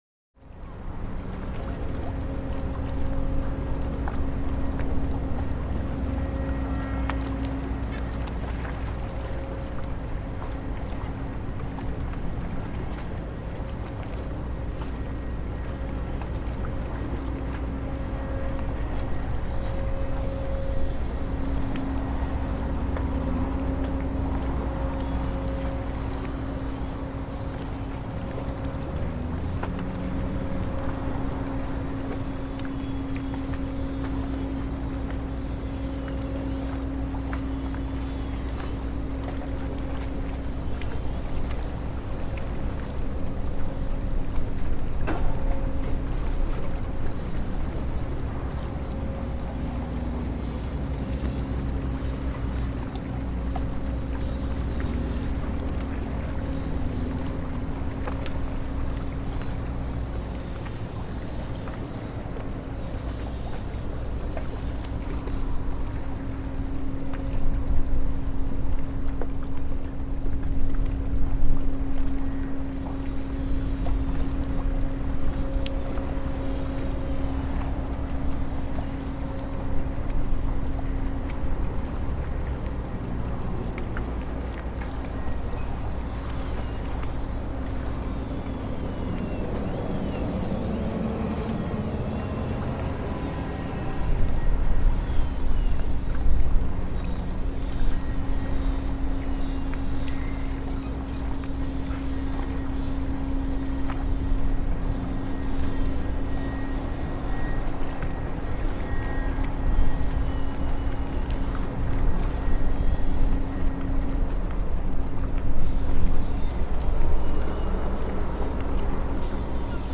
Through a series of soundwalks